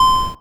buzzer_correct.wav